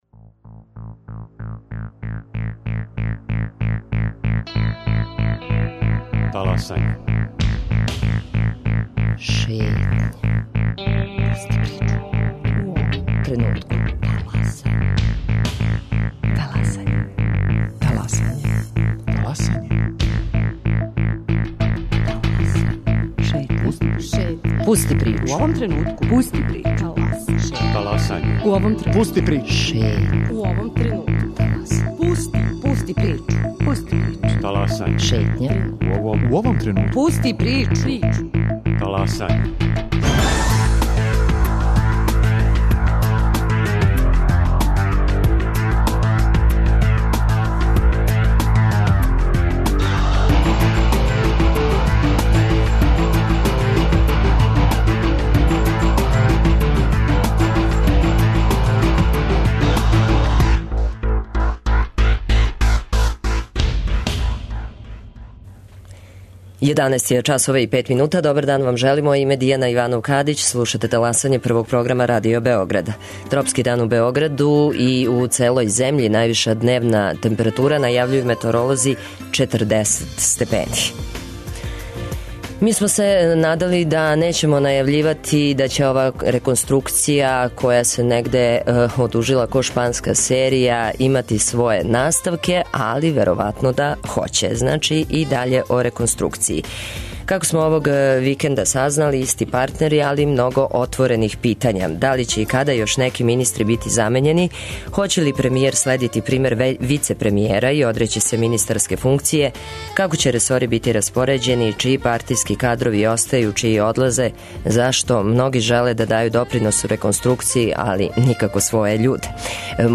Гост: Зоран Бабић, заменик шефа посланичке групе СНС.